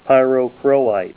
Help on Name Pronunciation: Name Pronunciation: Pyrochroite + Pronunciation
Say PYROCHROITE Help on Synonym: Synonym: ICSD 23591   PDF 18-787